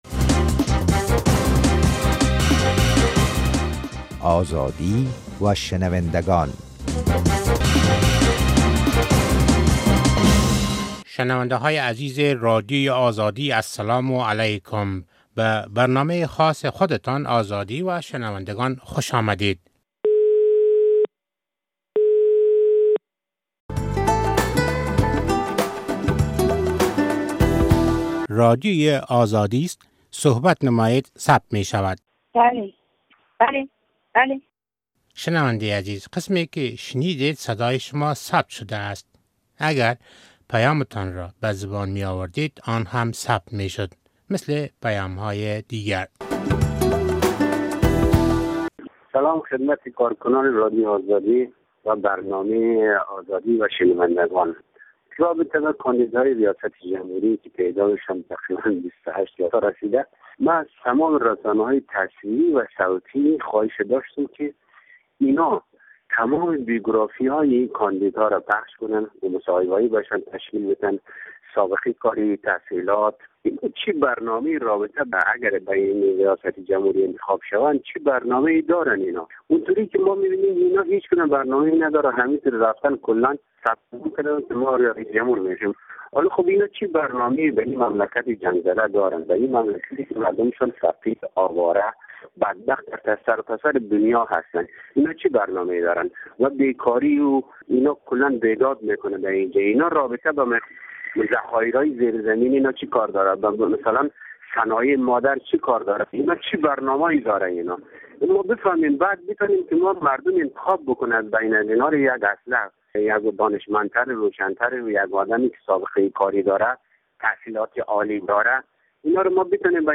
برنامهء این هفتهء "آزادی و شنونده گان" به پیام های شنونده گان رادیو آزادی که در مورد انتخابات آیندهء ریاست جمهوری افغانستان گذاشته اند، اختصاص داده شده است. در این برنامه همچنان می توان پیام های را نیز در مورد دشواری های موجود در اردوی ملی افغانستان شنید.